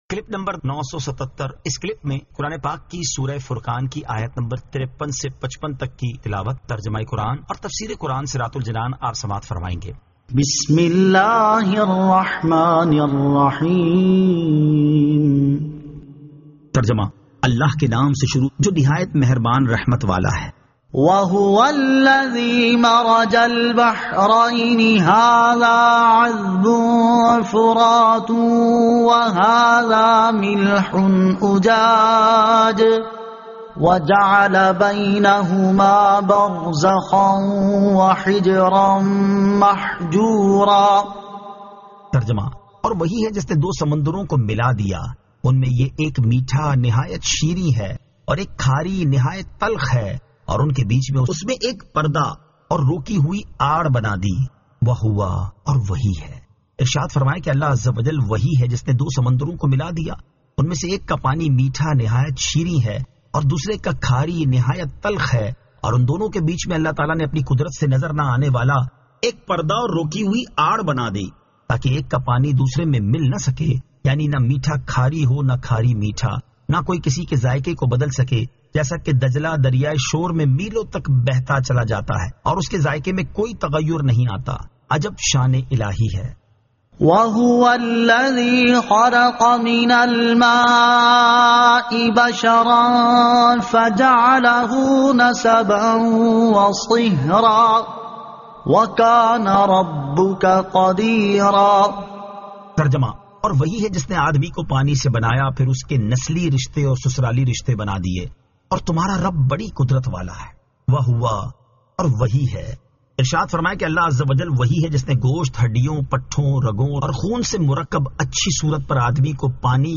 Surah Al-Furqan 53 To 55 Tilawat , Tarjama , Tafseer